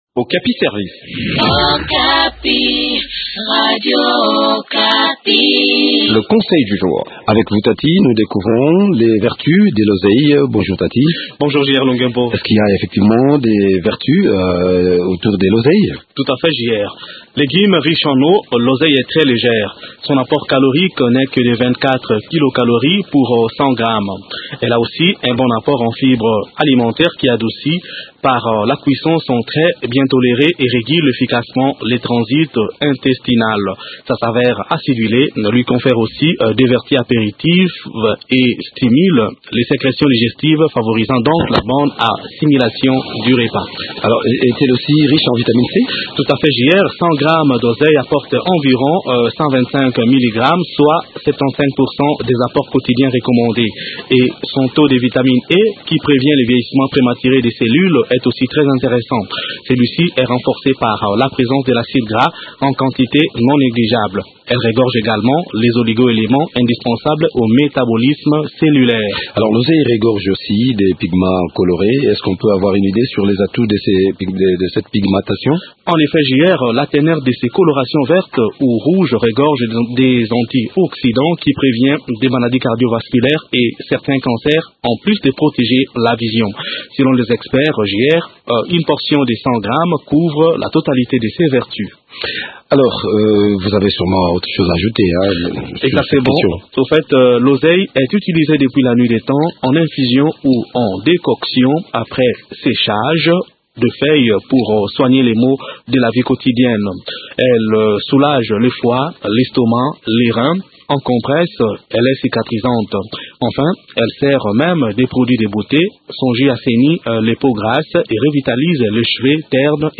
Réponses  dans cette chronique